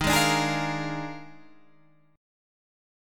EbM7sus2sus4 chord